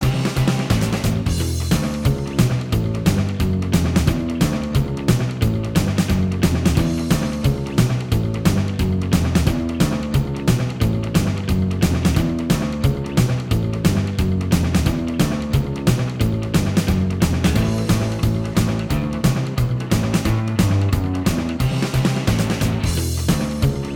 Minus Solo Guitar Rock 'n' Roll 3:01 Buy £1.50